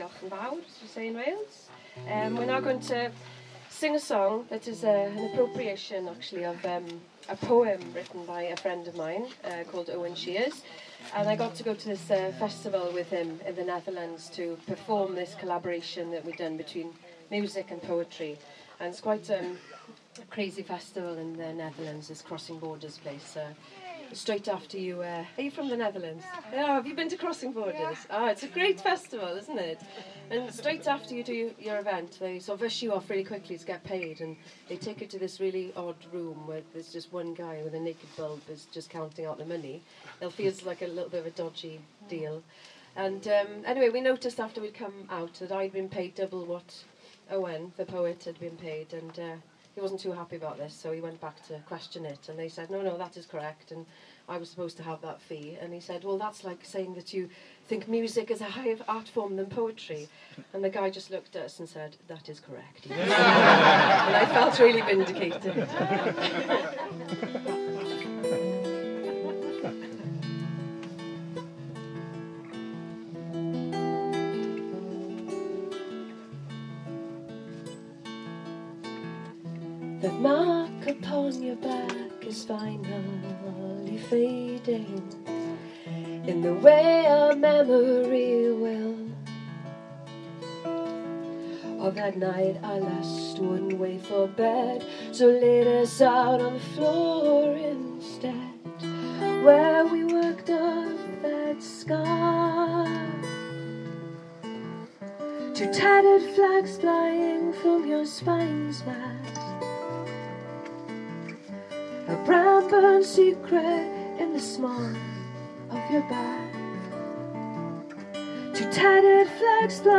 Campfire music